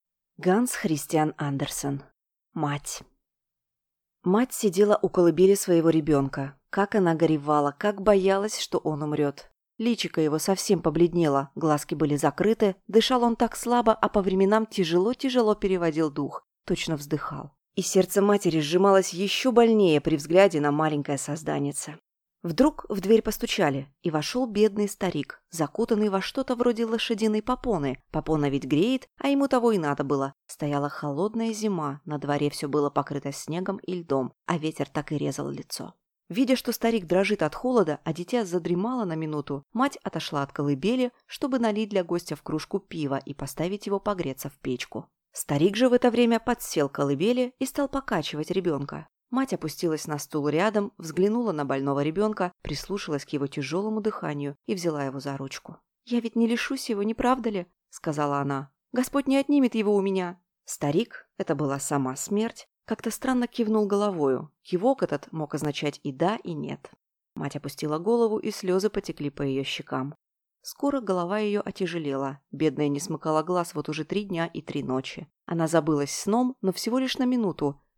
Аудиокнига Мать | Библиотека аудиокниг